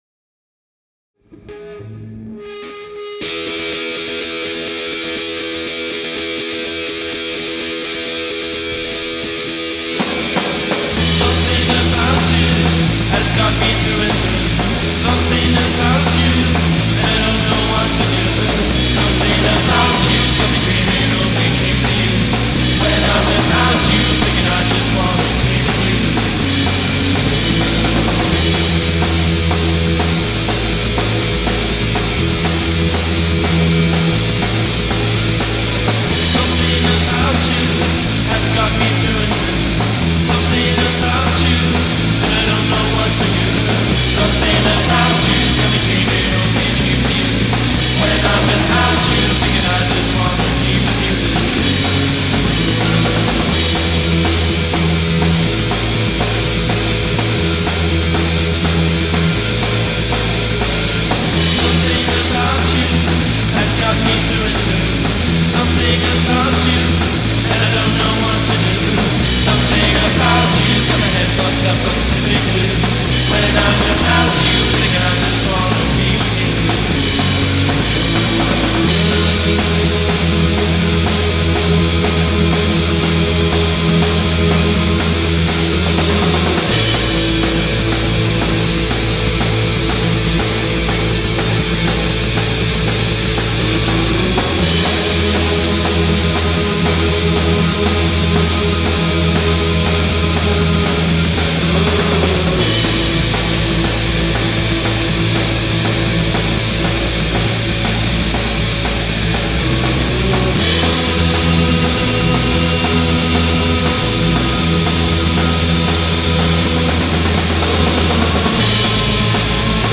Sembra di ferro.